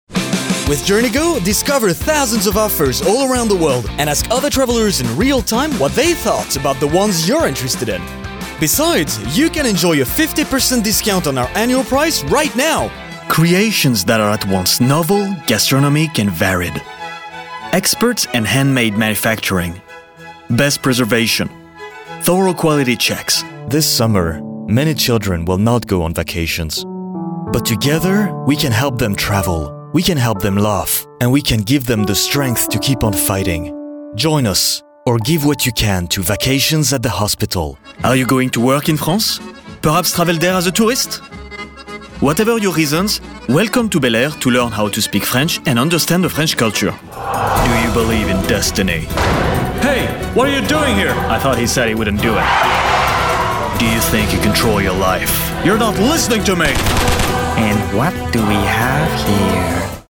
Commercial Demo
I can also take a higher pitch to sound more energetic, younger and friendly, which can come in handy for commercials.